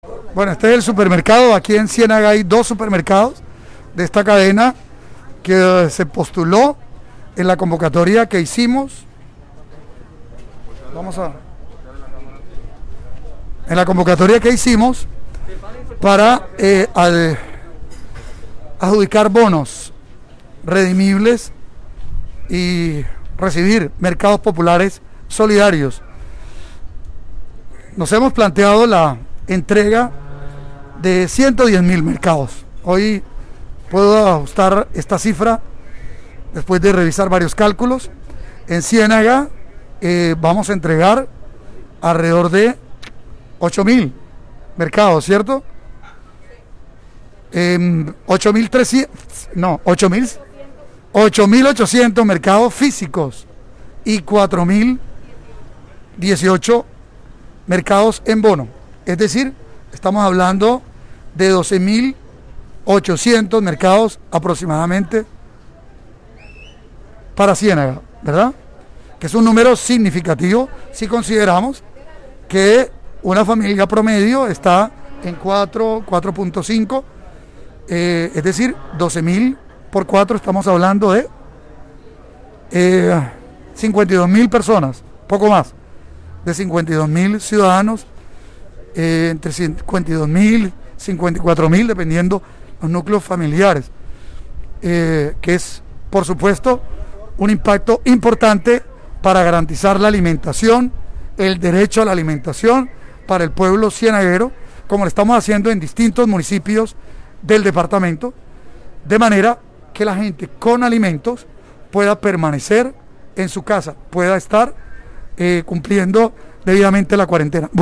AUDIO-GOBERNADOR-SOBRE-MERCADOS-EN-CIÉNAGA-1.mp3